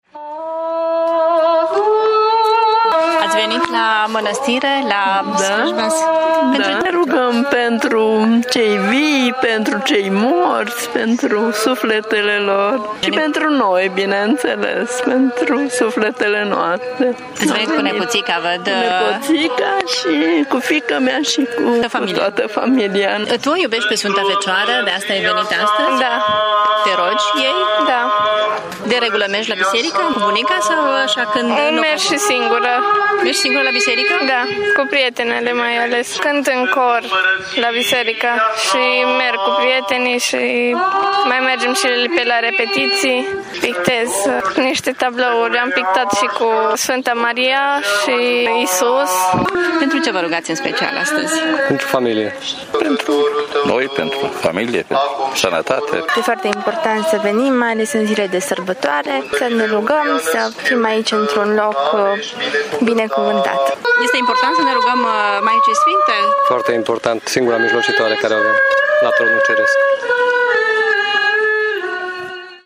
Sute de mureșeni au prăznuit-o pe Maica Domnului la Mănăstirea Recea
Oamenii s-au rugat pentru familie și pentru sănătate singurei mijlocitoare la Tronul Ceresc: